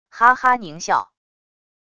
哈哈狞笑wav音频